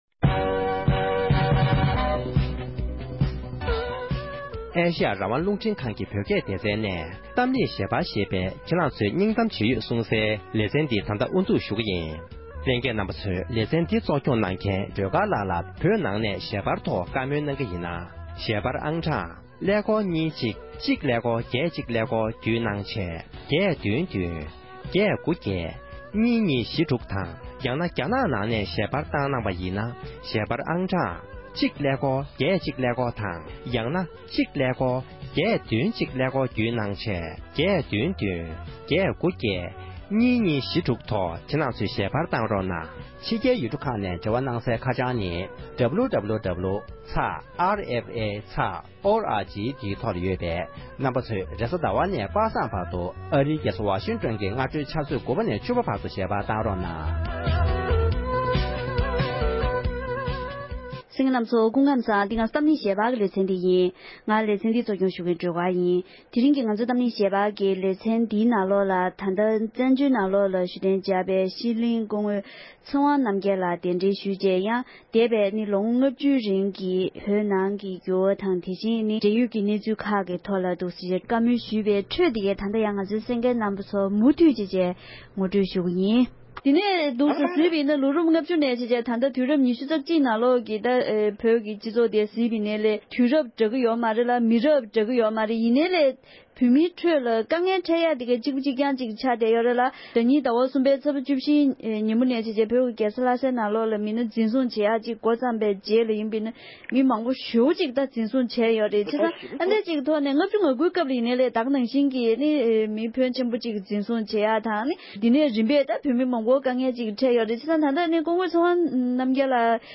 གླེང་མོལ་ཞུས་པ།